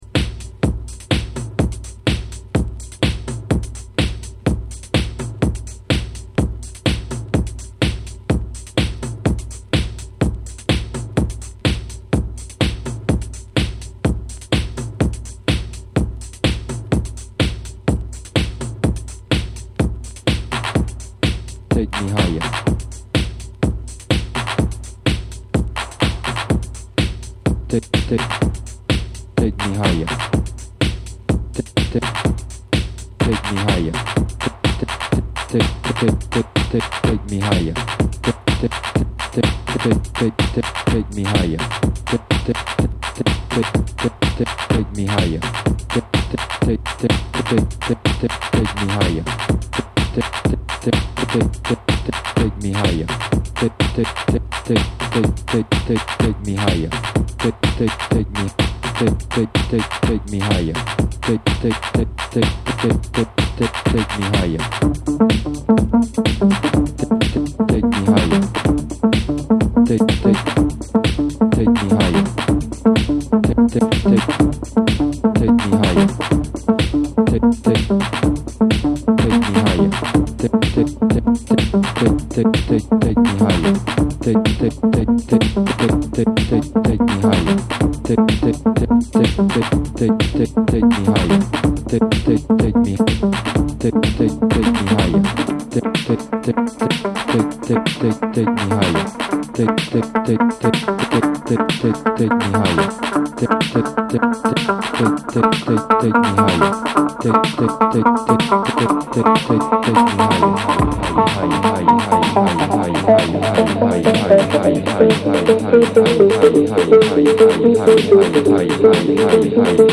ジャズ・ファンク〜 ディスコ等あらゆる ブラックミュージックを昇華した新世代ダンスミュージック！